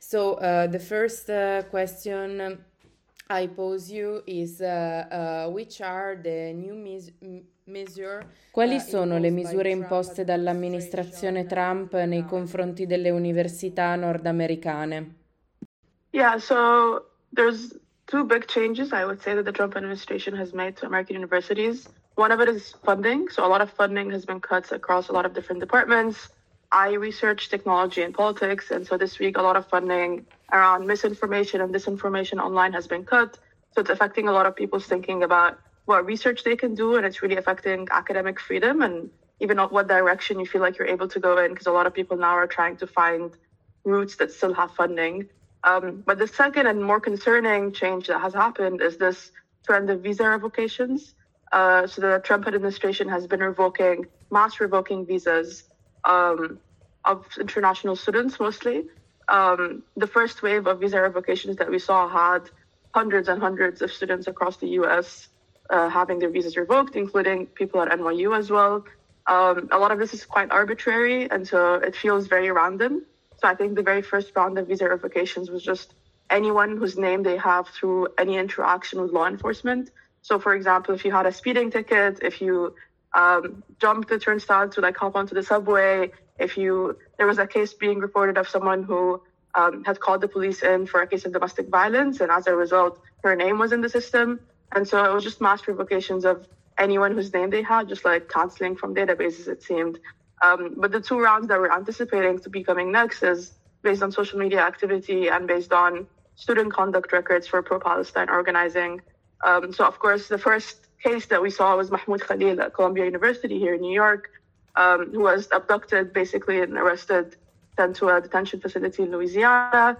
In questa intervista con una studentessa della New York University proviamo a fare il punto su quella che viene raccontata come l'offensiva di Trump contro le università americane – una offensiva che sembra colpire in modo particolarmente violento i settori più militanti e politicizzati degli studenti, dei docenti e del personale universitario.